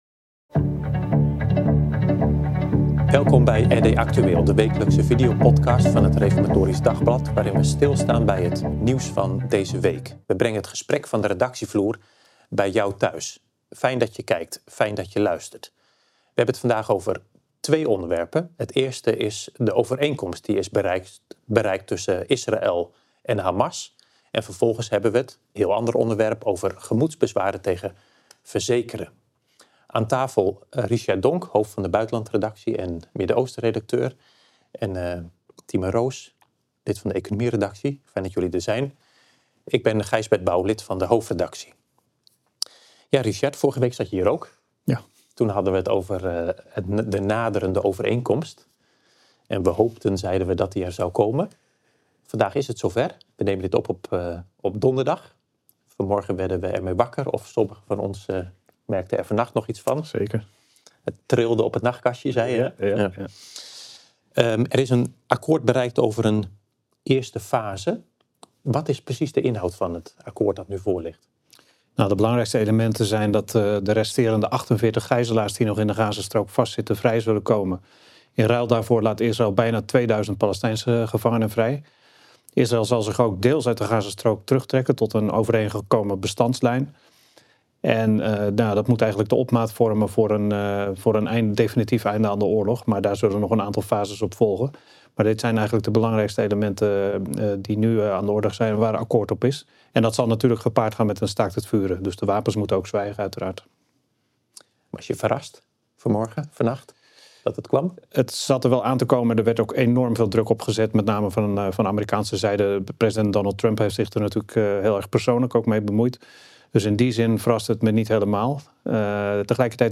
De wekelijkse videopodcast waar we de gesprekken van de redactievloer bij je thuis brengen. Met elke aflevering drie onderwerpen uit het nieuws van afgelopen week.